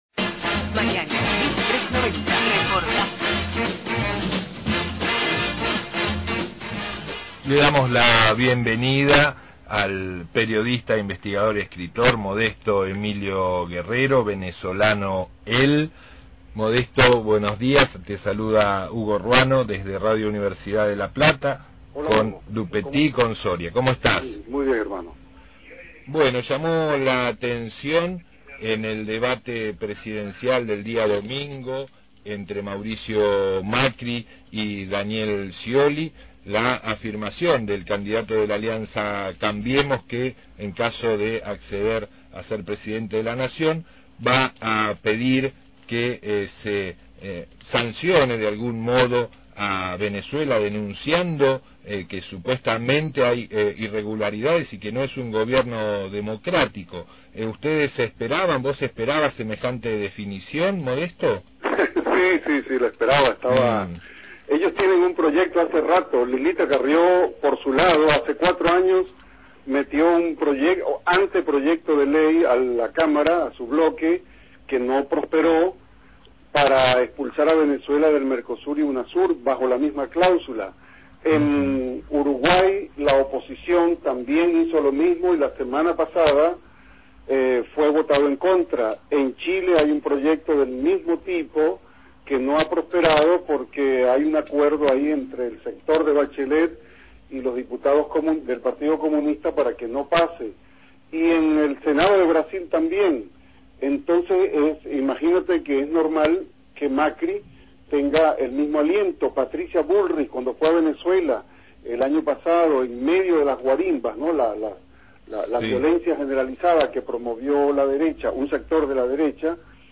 periodista, investigador y escritor venezolano